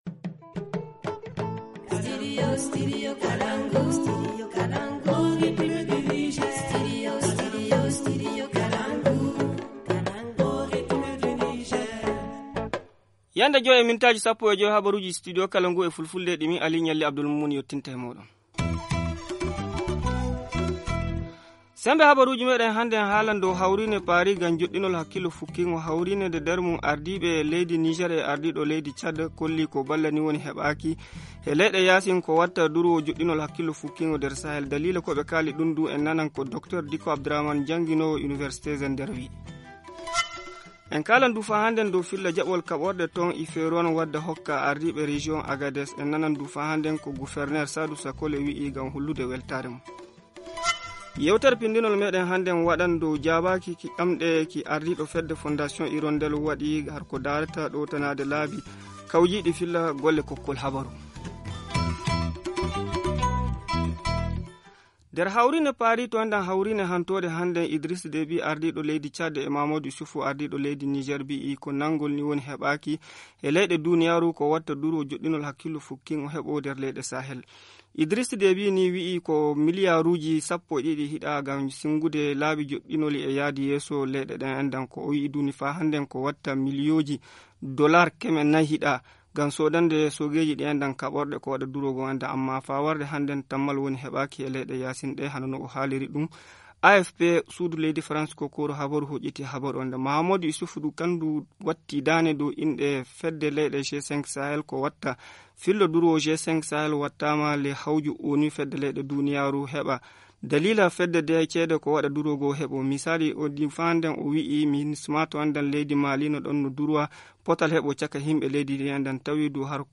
Le journal du 13 novembre 2019 - Studio Kalangou - Au rythme du Niger